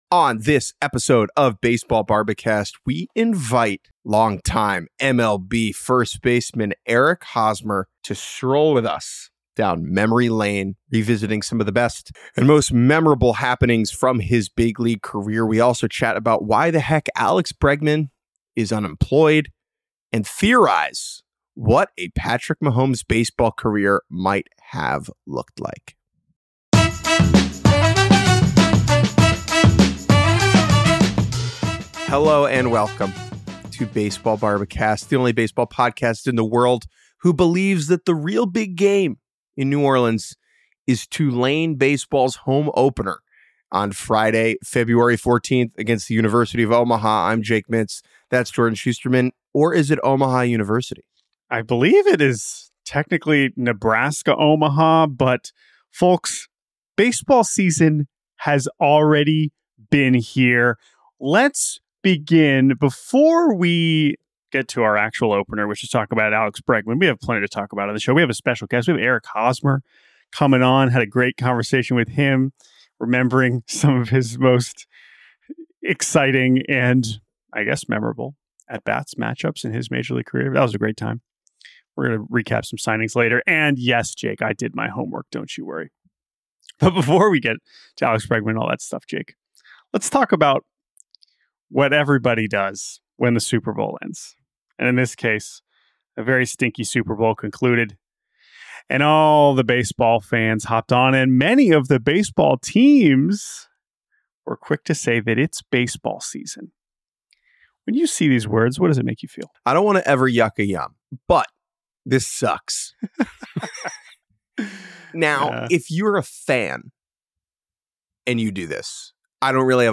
Astros, Red Sox & Cubs in on Alex Bregman + former All-Star Eric Hosmer interview 1 hour 6 minutes Posted Feb 10, 2025 at 7:10 pm .